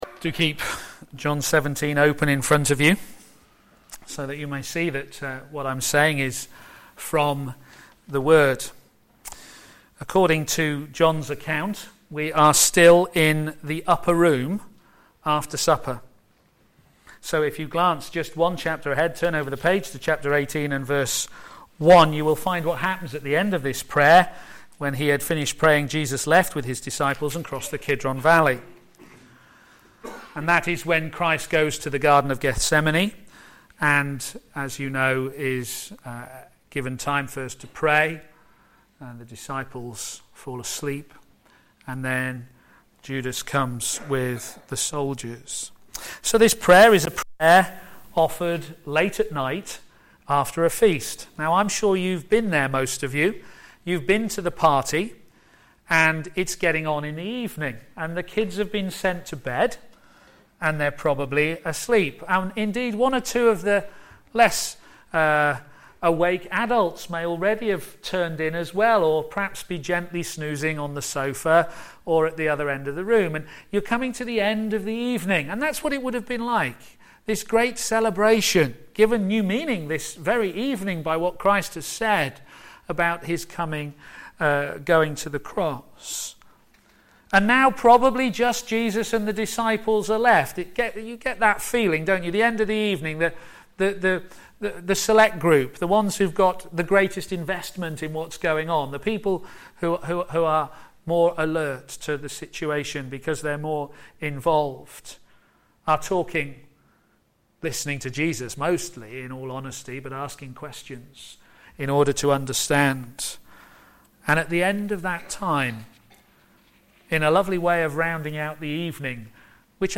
Media for a.m. Service on Sun 08th Mar 2015 10:30
Series: John on Jesus Theme: Jesus prays or Himself Sermon